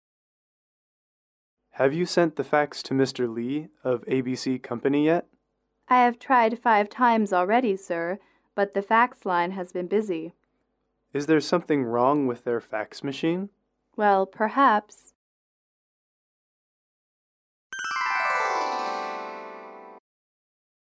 英语口语情景短对话42-2：发送传真(MP3)